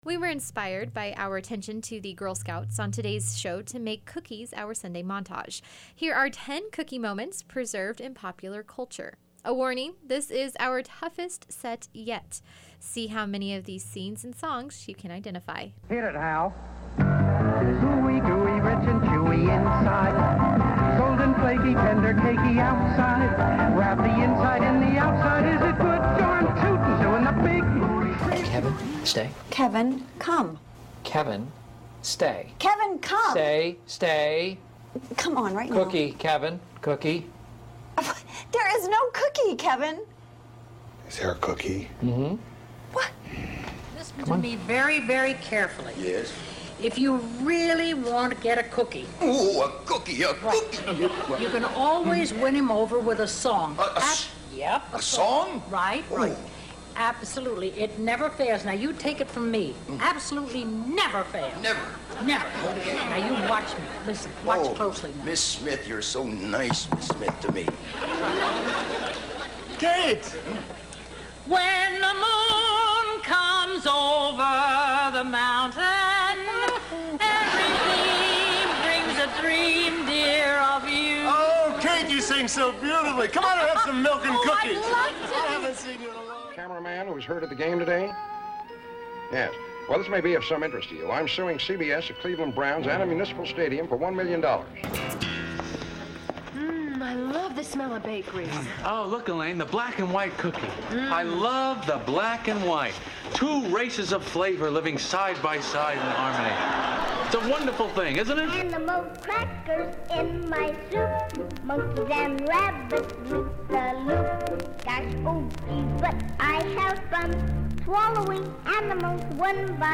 Here is our list of ten cookie references from our montage this morning.
1. A commercial for the Fig Newton cookie from the 1970s.
6. Shirley Temple sings "Animal Crackers" . . . they’re cookies, right?
Transition Music:
Prokofiev's Symphony No. 5